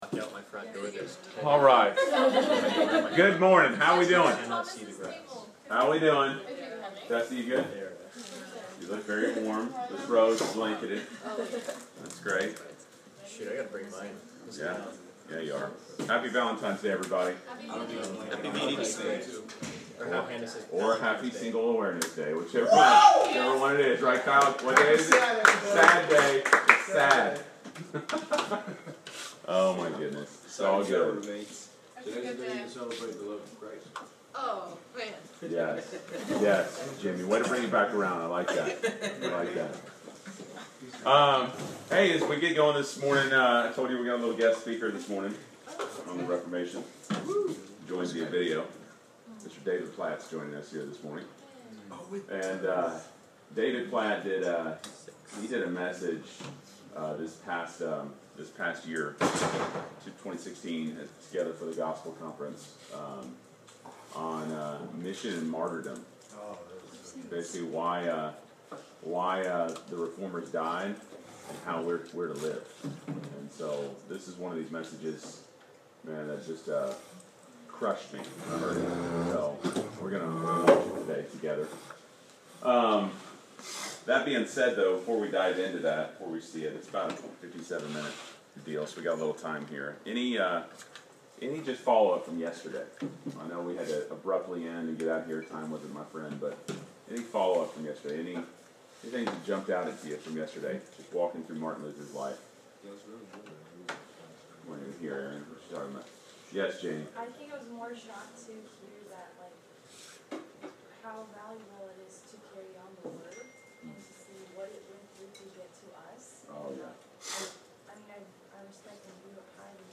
Class Session Audio February 14